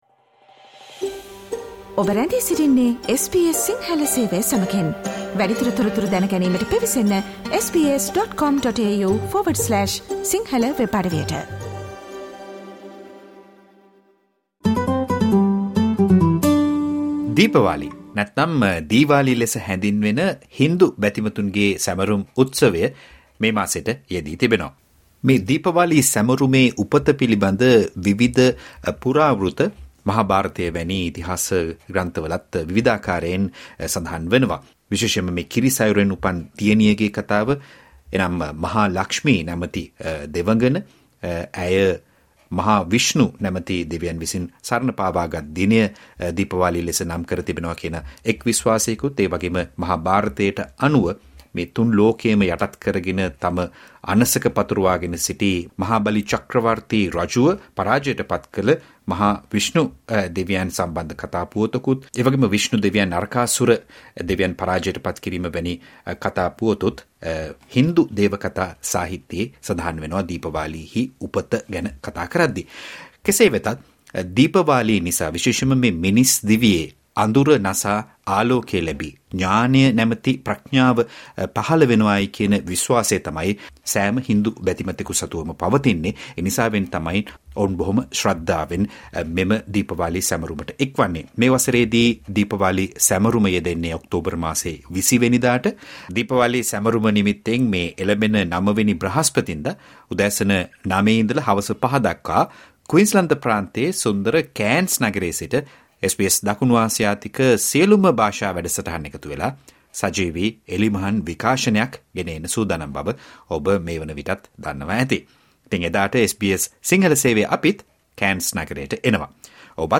කතාබහ
at the SBS Sydney studio.